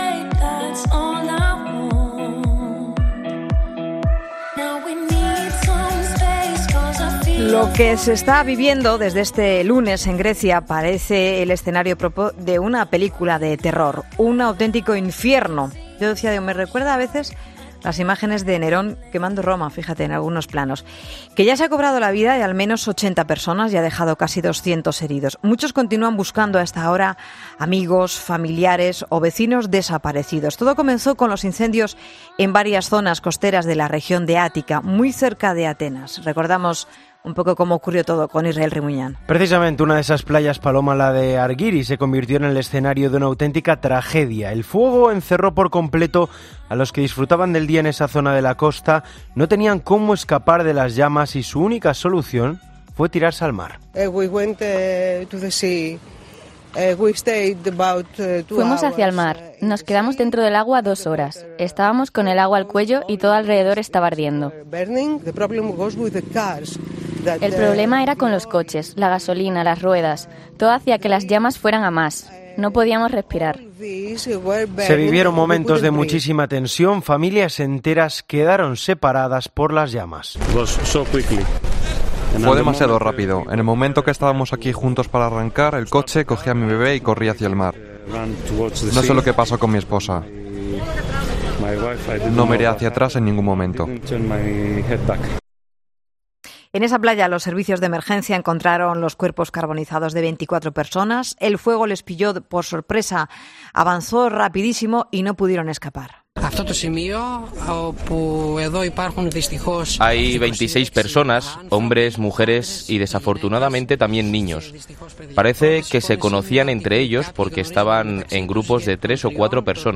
Hablamos en 'La Tarde' con